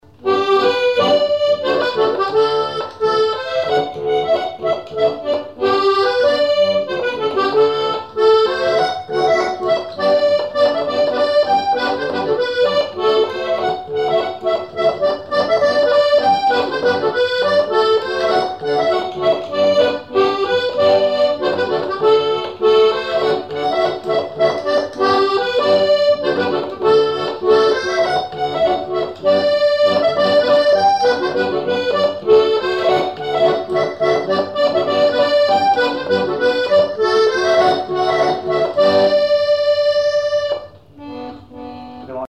Chants brefs - A danser
danse : polka
Témoignages et chansons